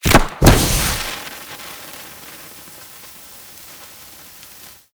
Incendiary_Near_05.ogg